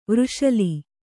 ♪ vřshali